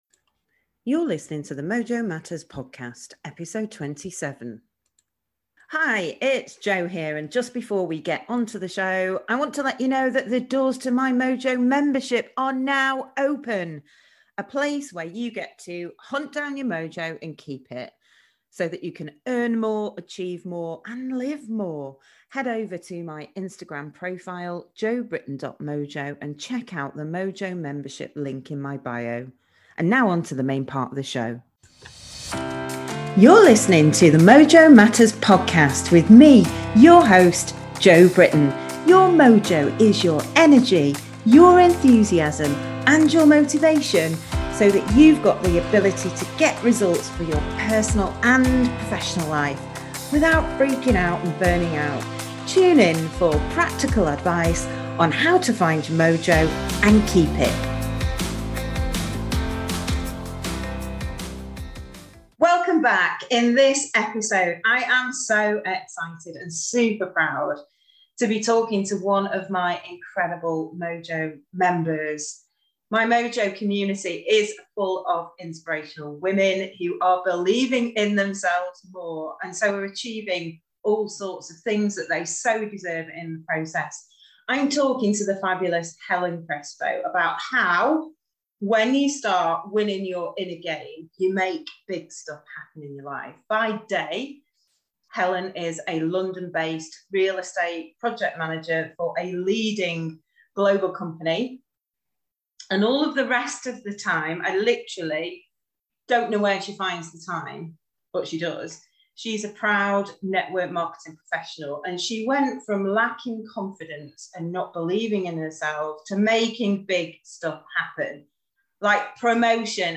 In this episode, I talk to one of my incredible members of my Mojo Community which is full of inspirational women who are believing in themselves more and so are achieving all the things they so deserve.